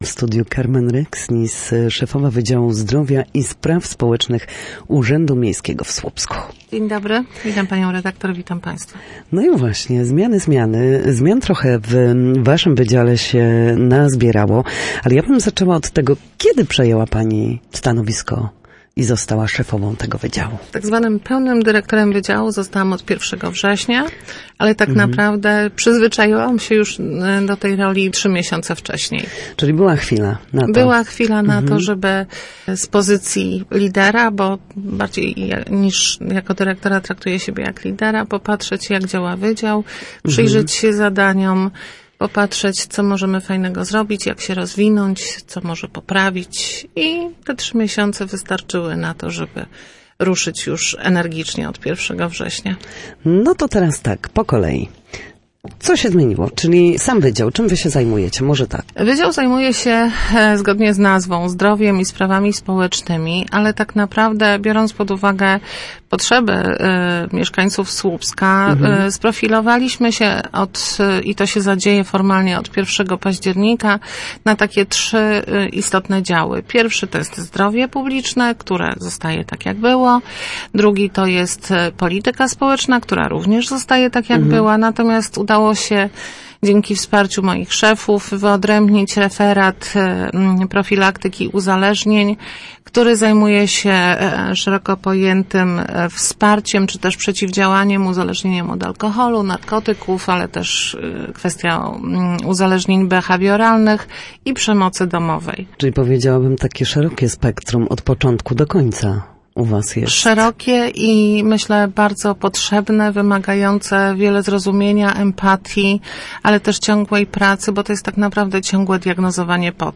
W audycji „Na Zdrowie” nasi goście, lekarze i fizjoterapeuci odpowiadają na pytania dotyczące najczęstszych dolegliwości, podpowiadają, jak wyleczyć się w domowych warunkach